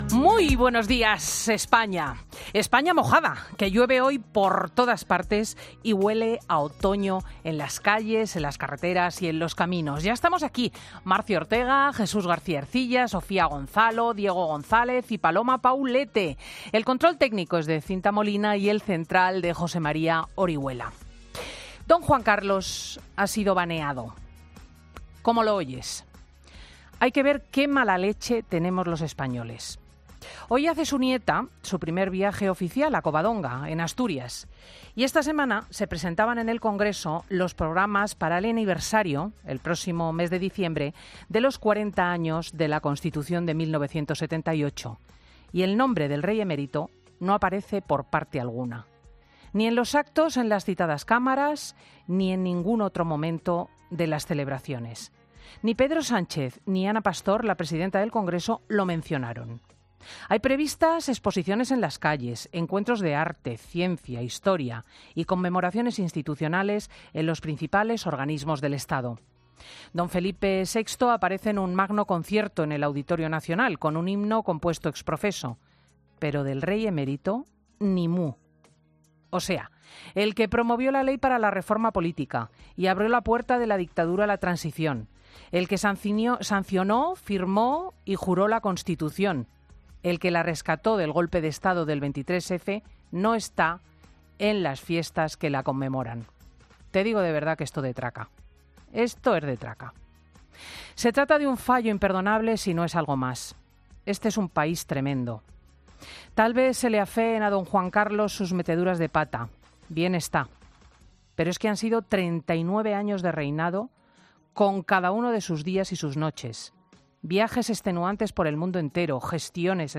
Monólogo de Cristina López Schlichting